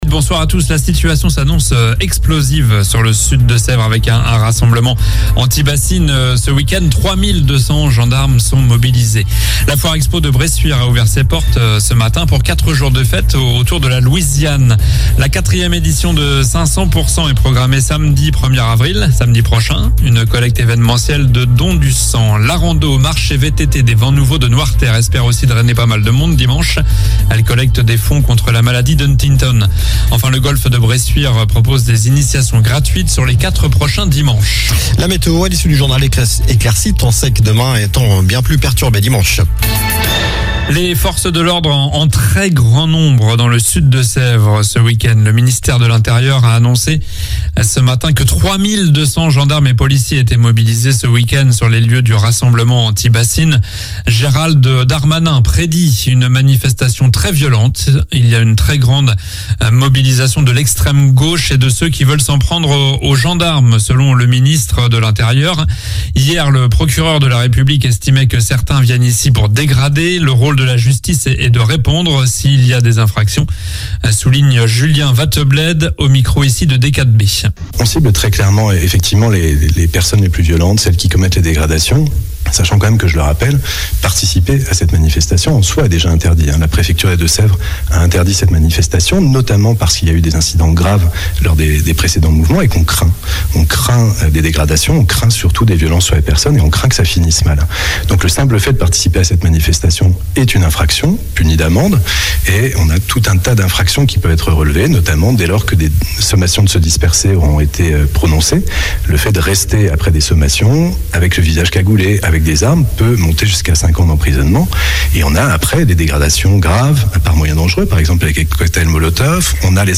Journal du vendredi 24 mars (soir)